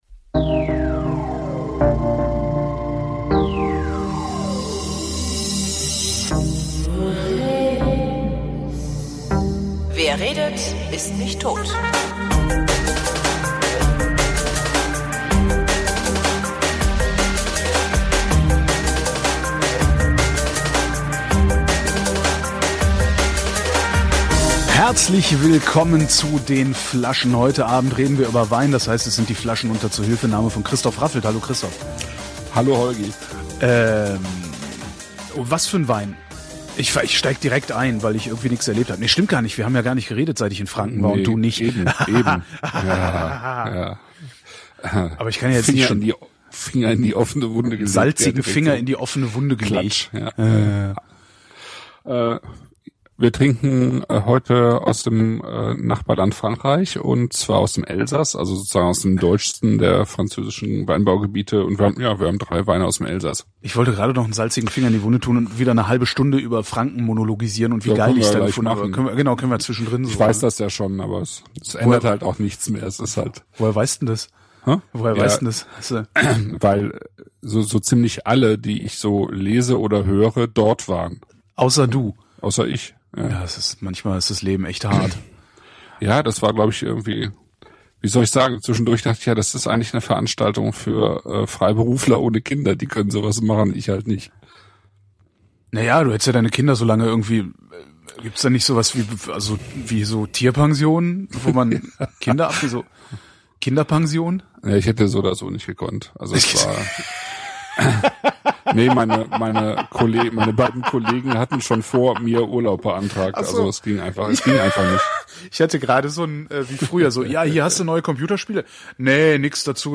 Wir hatten Technikprobleme und unser einziges Backup ist direkt aus dem Stream. Bitte entschuldigt die mindere Qualität.